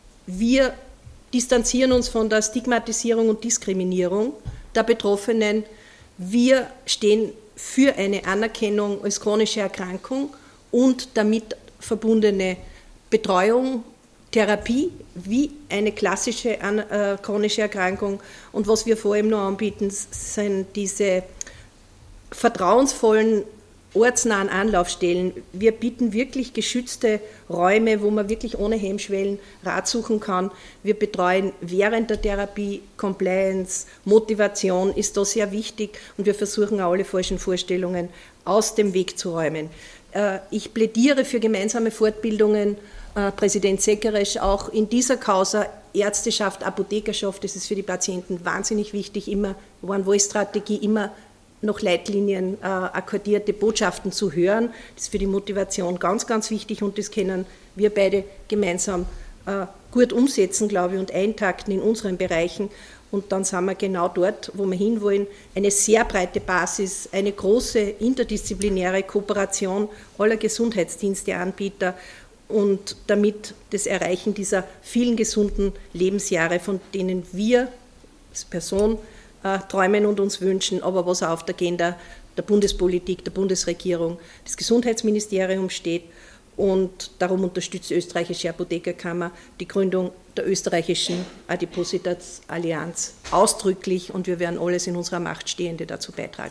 .mp3 O-Ton Dateien der Pressekonferenz vom 21.06.2022: